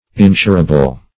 Insurable \In*sur"a*ble\, a. [From Insure.]